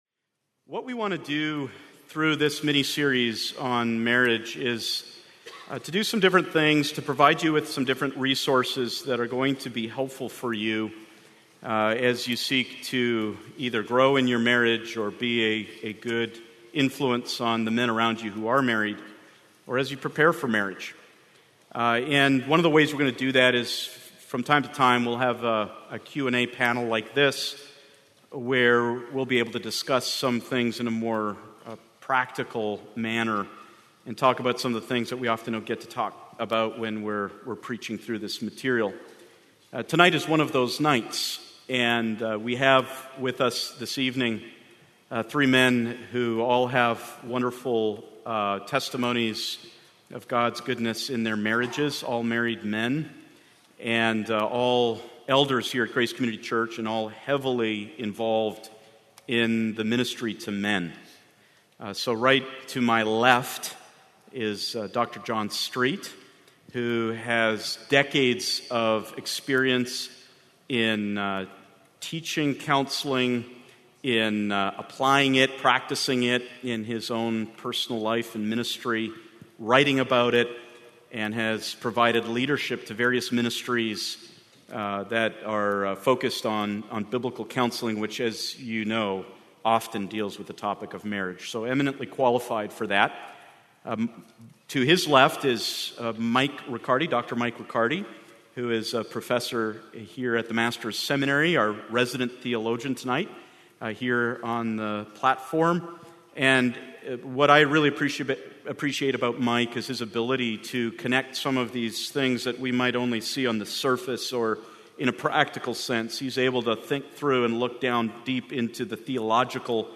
The Pillars of Marriage: Panel Discussion, Part 1